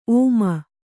♪ ōma